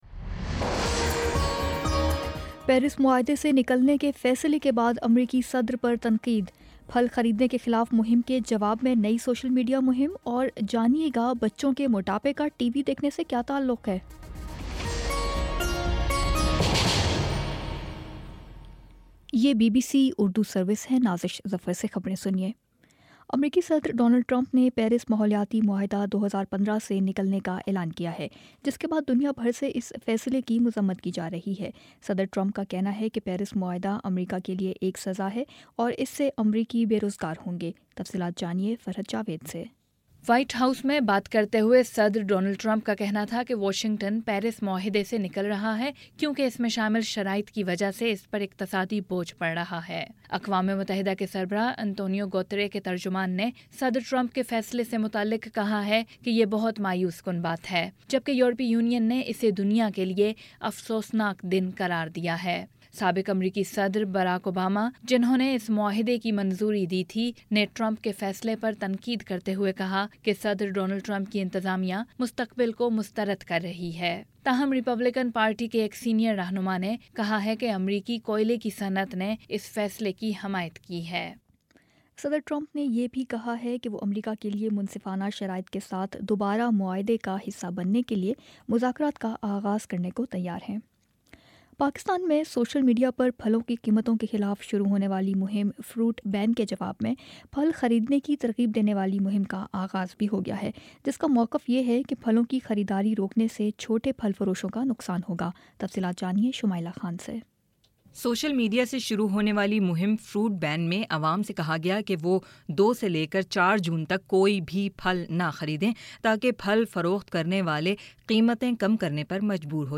جون 03 : شام پانچ بجے کا نیوز بُلیٹن